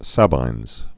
(săbīnz, -ĭnz, sābīnz)